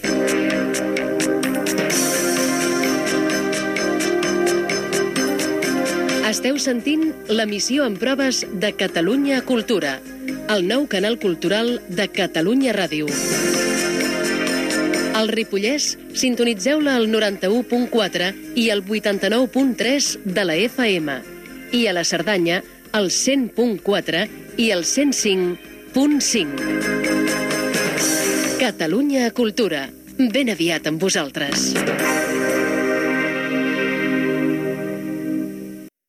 Indicatiu de l'emissió en proves, amb esment a algunes de les freqüències emprades.